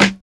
Snare (Gazzillion Ear).wav